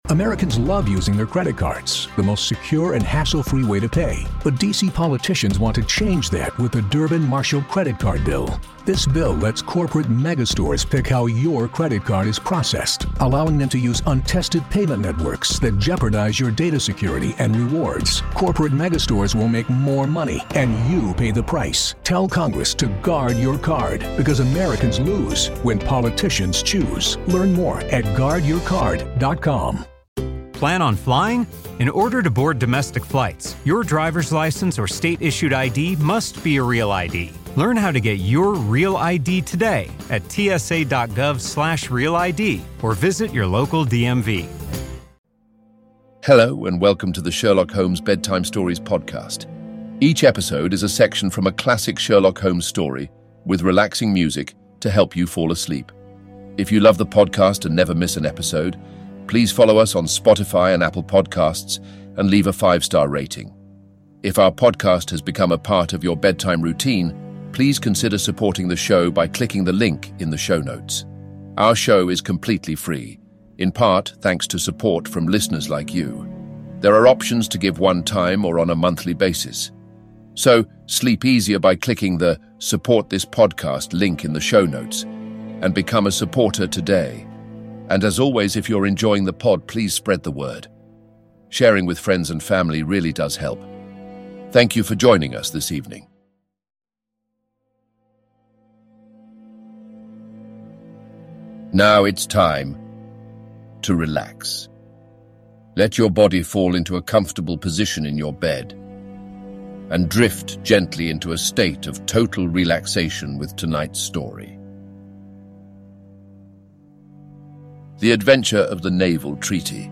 Each episode is a section of a classic Sherlock Holmes story, read in soothing tones and set to calming music to help you fall asleep.-----Help us keep this podcast free!